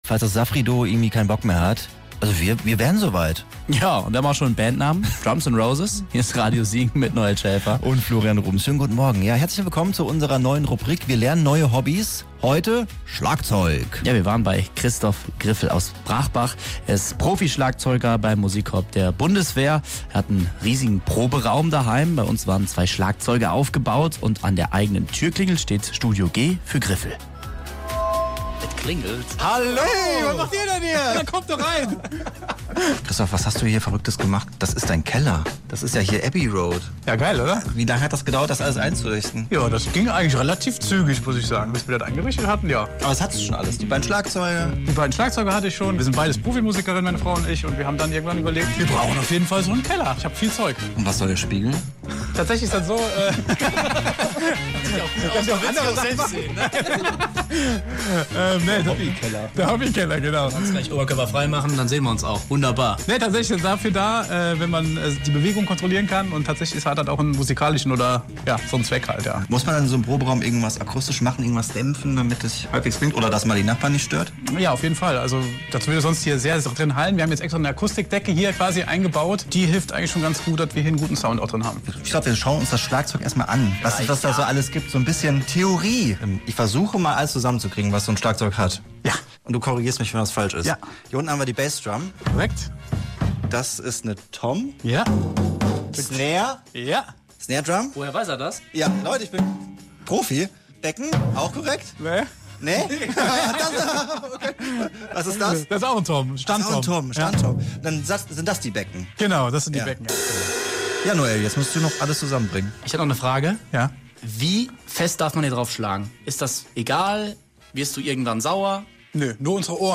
Neues Hobby: Schlagzeug für Anfänger
In der ersten Stunde stand das Schlagzeugspielen auf dem Stundenplan.